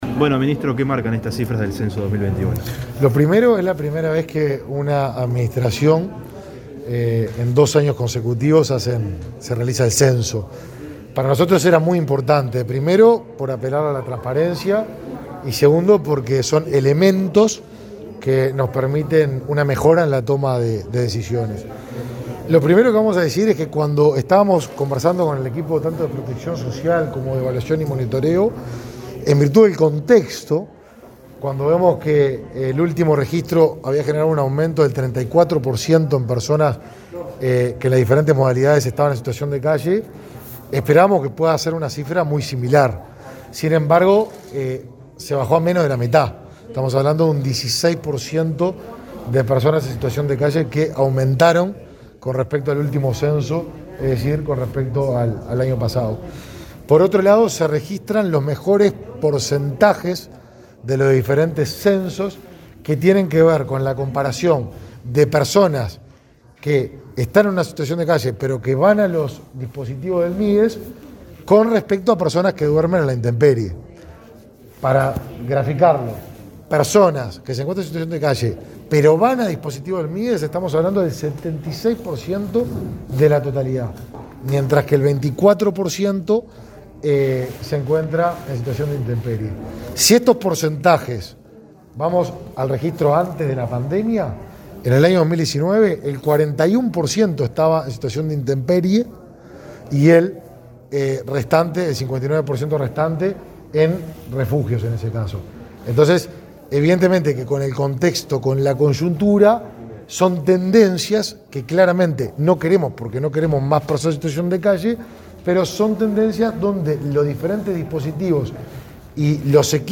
Declaraciones a la prensa del ministro Lema sobre censo de personas en situación de calle